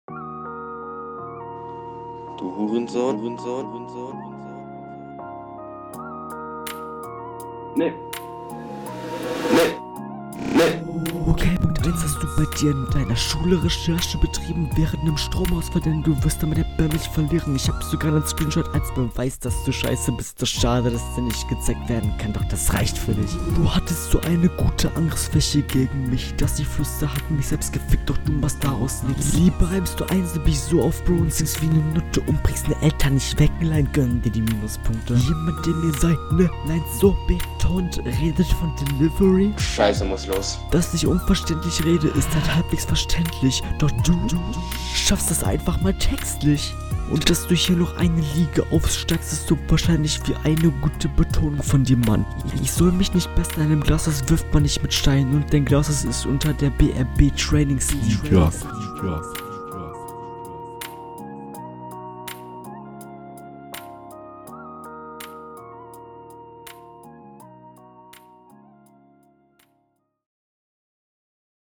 Hier auch deutlich verständlicher.
Hier wieder geflüster.